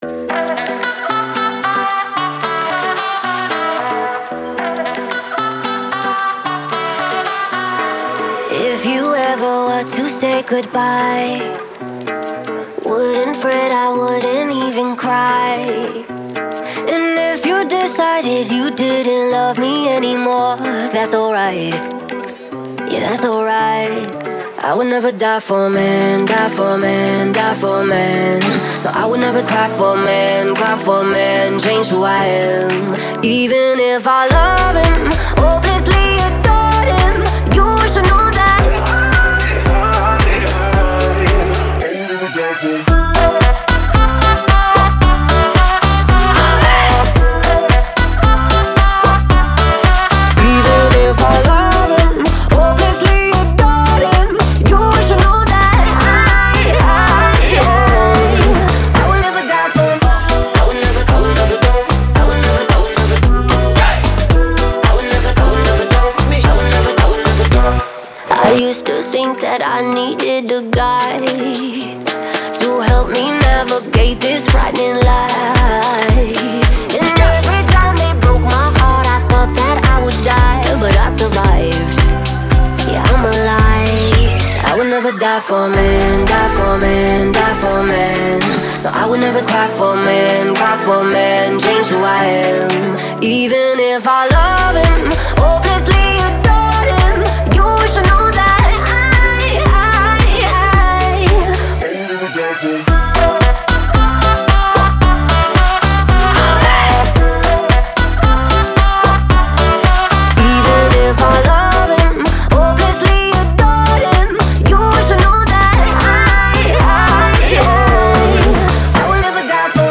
яркая и энергичная поп-песня